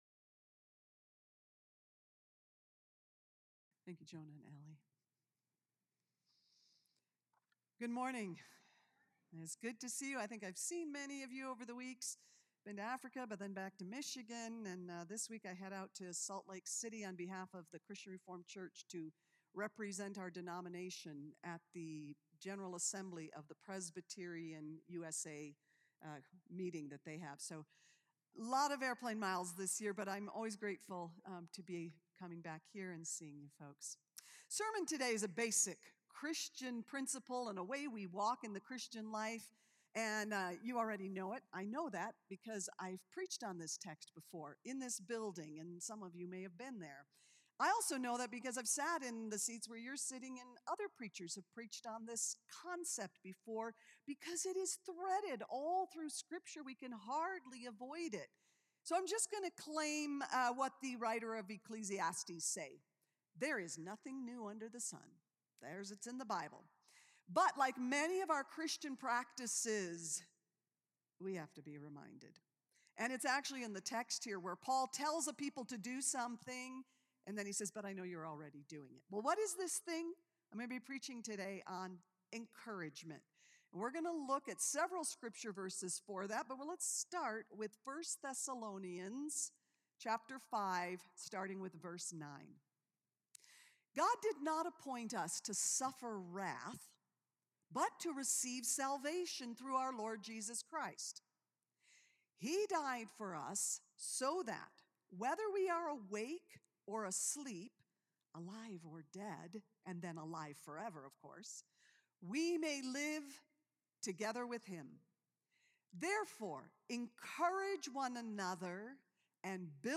A message from the series "Sunday Services."